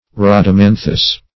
Rhadamanthus \Rhad`a*man"thus\, prop. n. [L., fr. Gr. ???.]